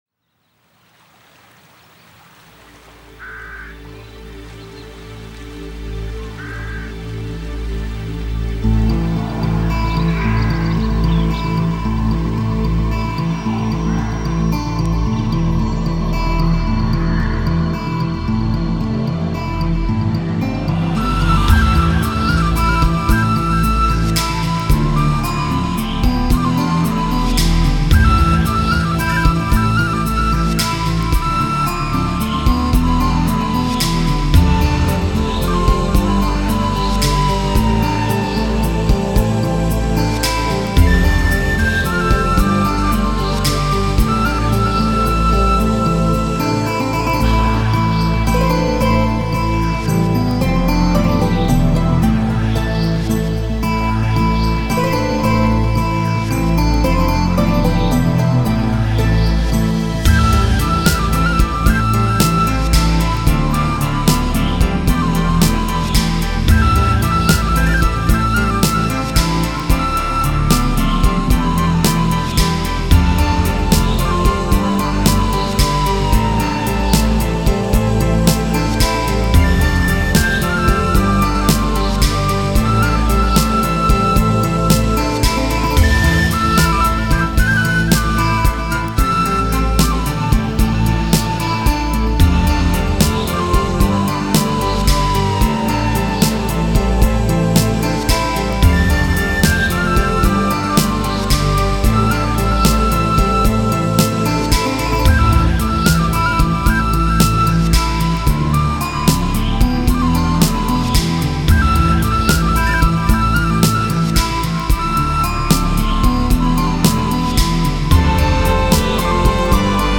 Celtic music
He does celtic/folk style music with a lot of atmosphere.